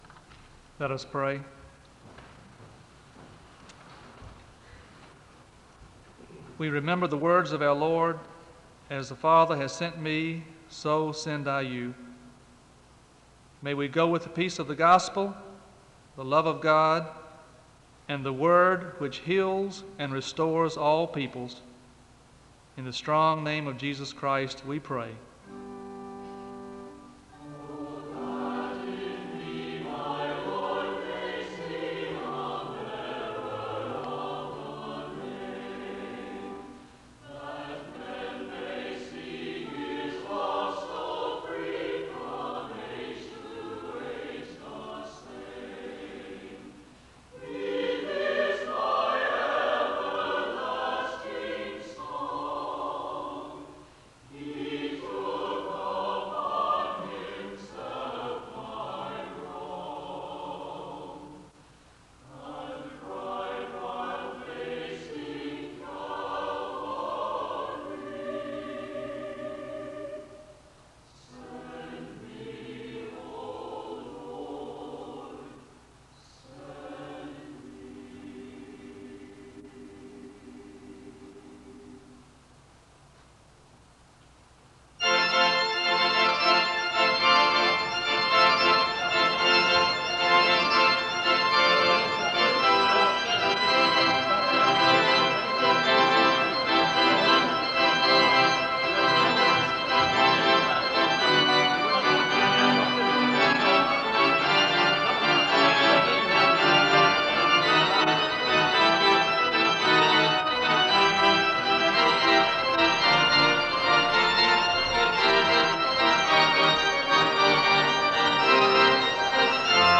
SEBTS Chapel